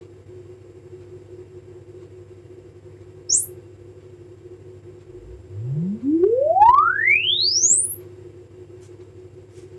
ピヨ〜ンって、こんな音が出て、分析が始まる。
前述の、CD、NAS、Room Fitの時の音源(空気録音)は、このマイクをPCに繋いで録っております。
tuning_test.mp3